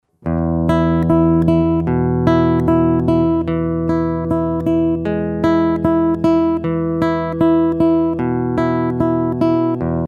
Очень уж они дают интересный тембр с пьезо звукоснимателей.
такой звук" посредством технологии COSM (через GK-3 на Roland GR-55) мне не удалось.